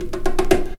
13DR.BREAK.wav